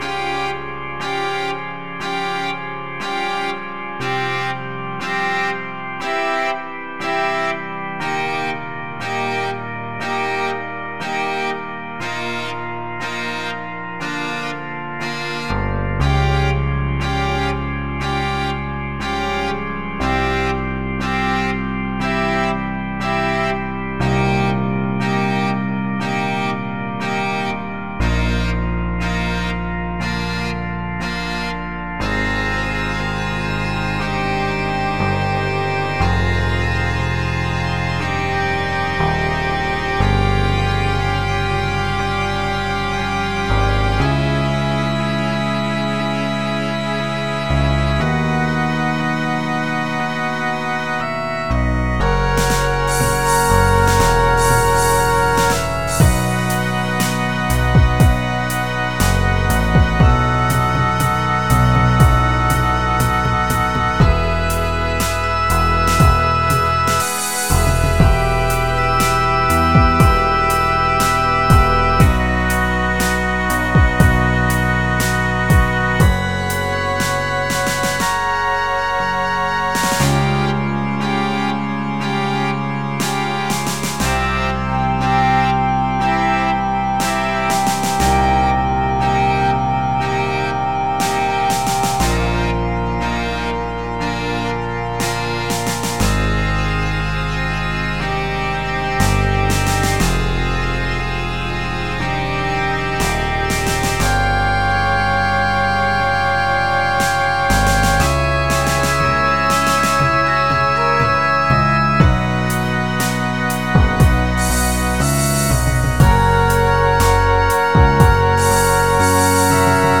Hier habe ich mir eine Midi Datei geladen und die einzelnen Spuren mit den entsprechenden Instrumenten belegt.